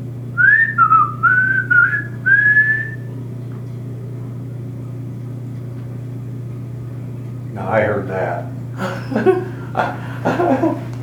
Whistle
An interesting response to our whistles.
whistleresponse2.wav